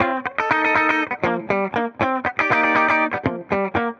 Index of /musicradar/dusty-funk-samples/Guitar/120bpm
DF_BPupTele_120-C.wav